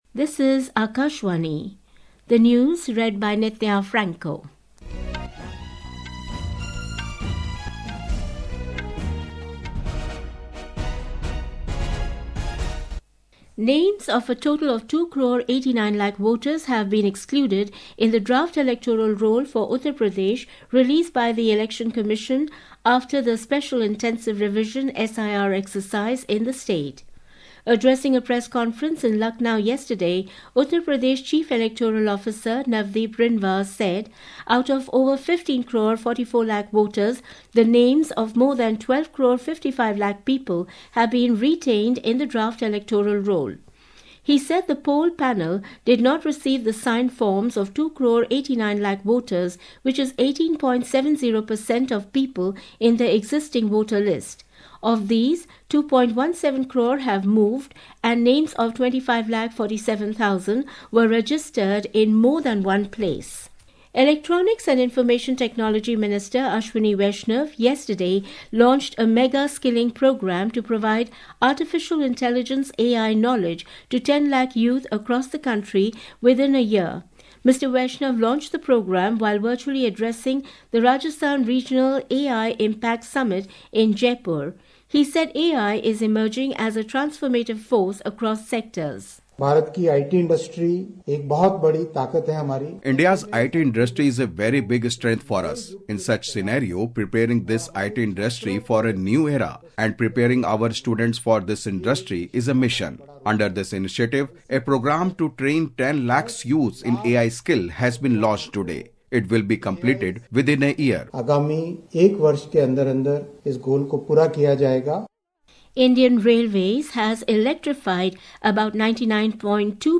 National Bulletins
Hourly News